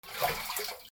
水音 水をすくい上げる
/ M｜他分類 / L30 ｜水音-その他
『パシャ』